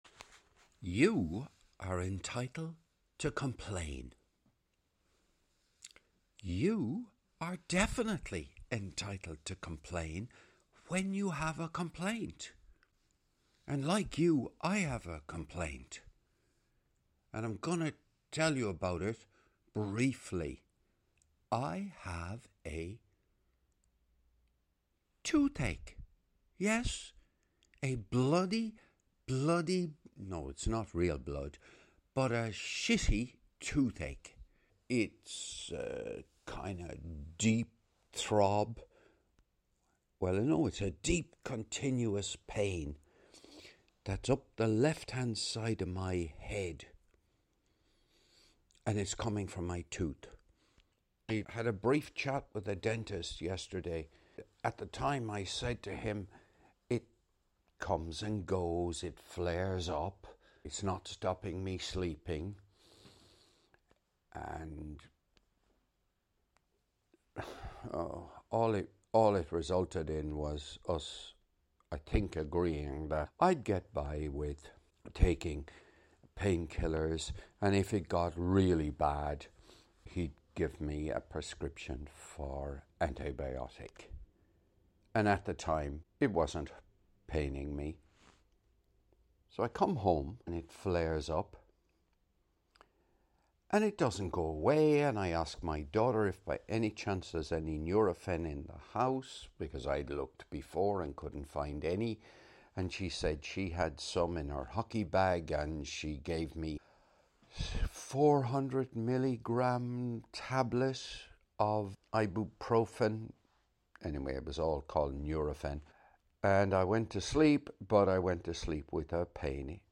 This was recorded while showering on the morning of Saturday 22nd of February 2025